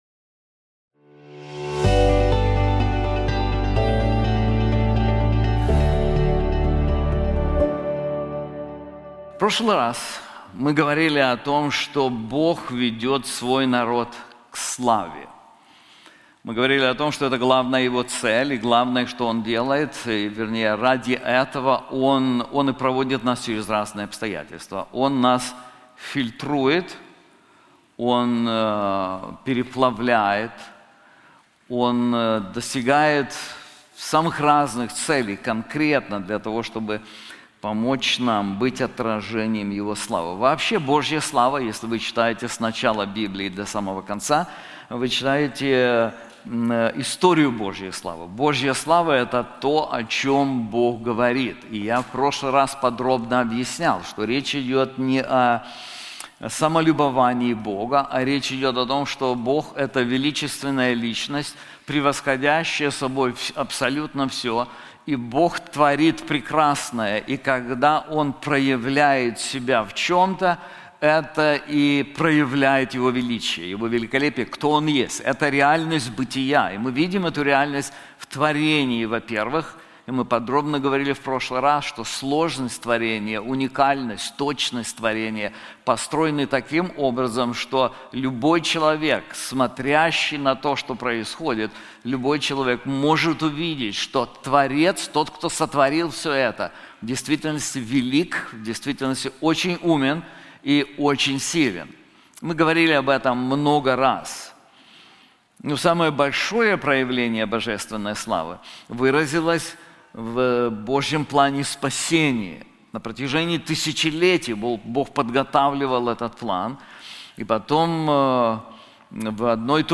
This sermon is also available in English:The Peace of God • Isaiah 48:12-22